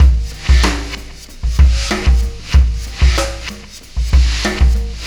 Black Hole Beat 02.wav